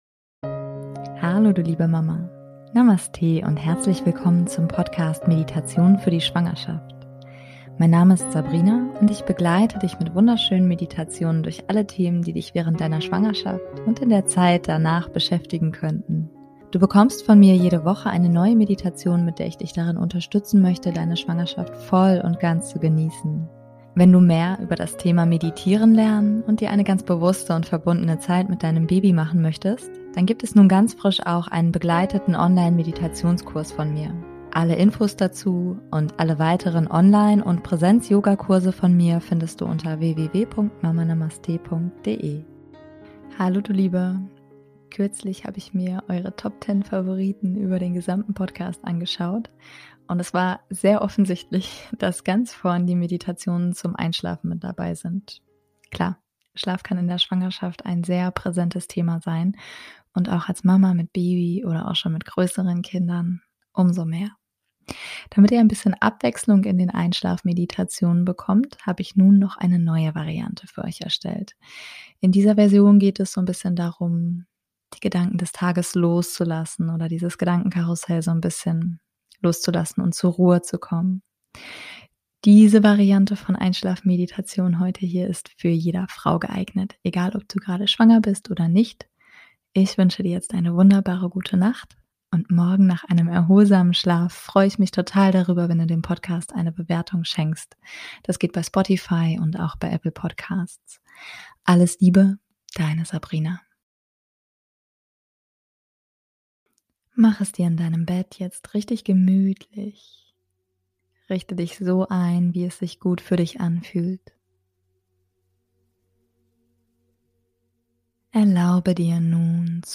#124 - Meditation zum Einschlafen Vol. 3 - Zur Ruhe kommen [Für Alle] ~ Meditationen für die Schwangerschaft und Geburt - mama.namaste Podcast